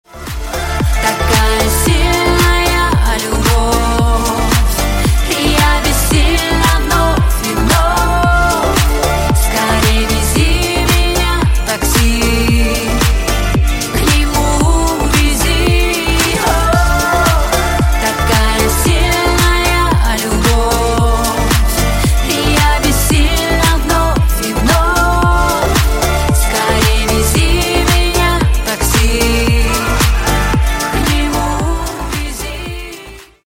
Русские Рингтоны » # Поп Рингтоны